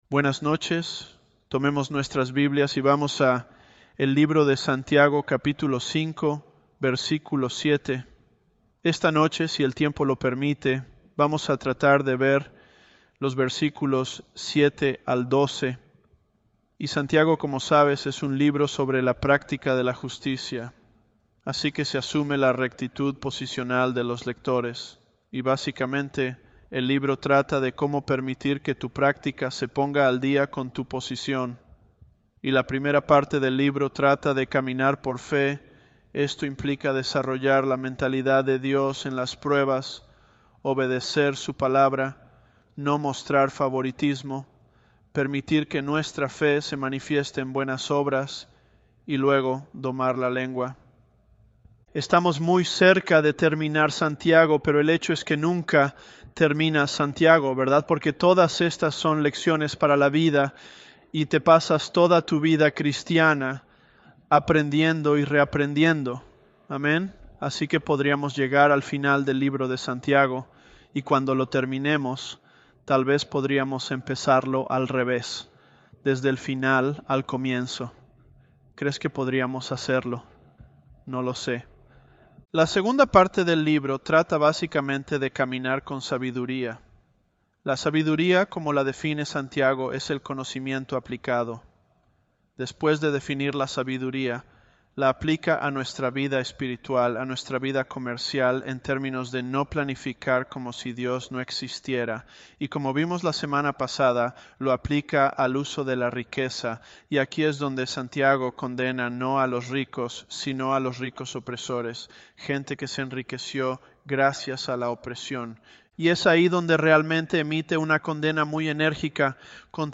ElevenLabs_James027.mp3